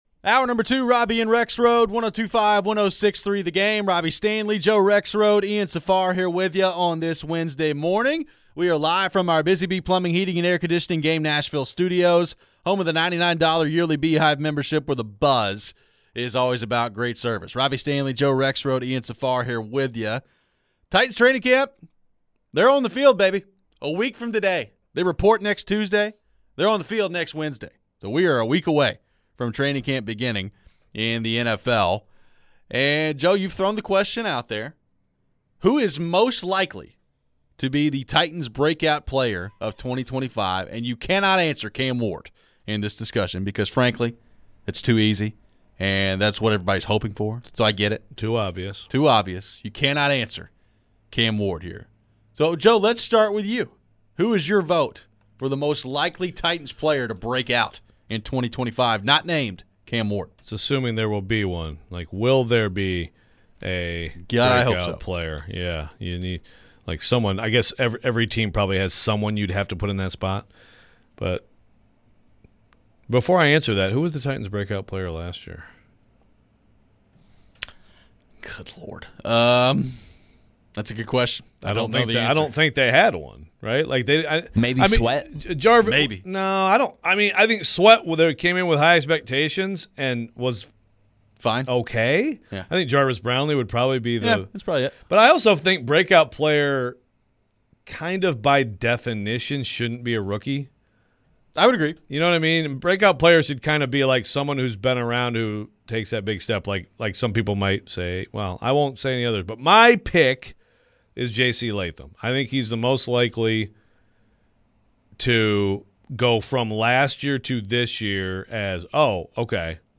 Other than Cam Ward, who on the Titans would we pick to have a 'breakout' season this year? Is the team lacking enough young talent? We head to the phones.